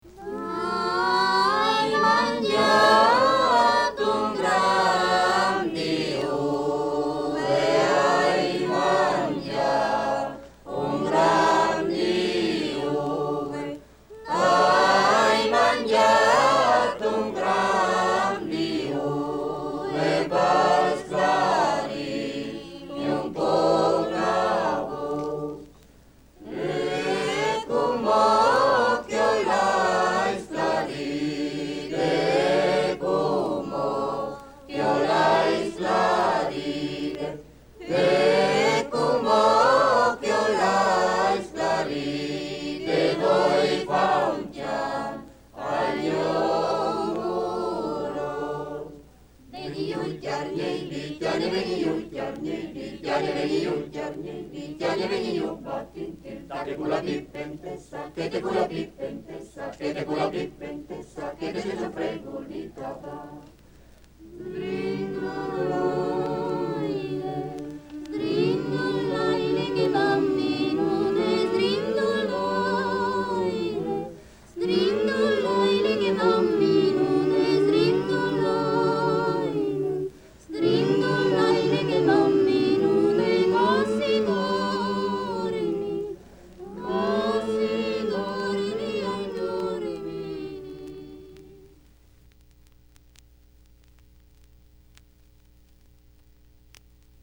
NORD E CENTRO ITALIA - DALLE RICERCHE DI ALAN LOMAX )1954)
11-tre villotte.mp3